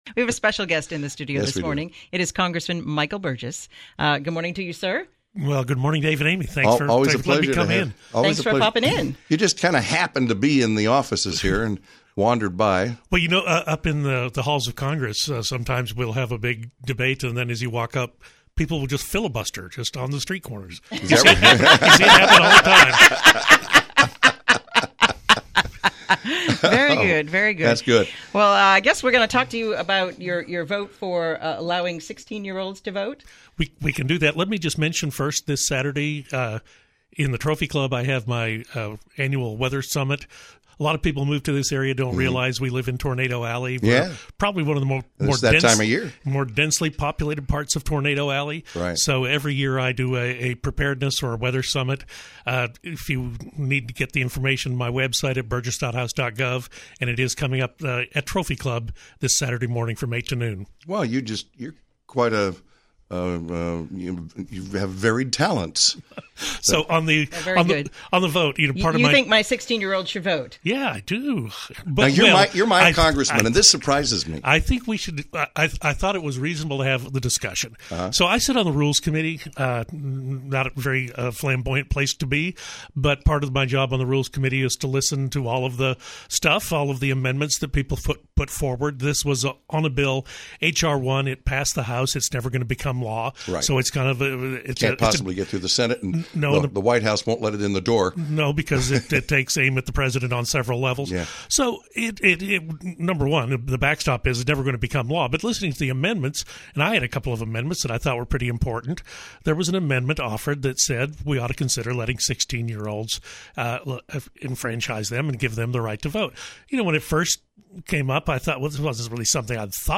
One lawmaker supporting that legislation is Congressman Michael Burgess.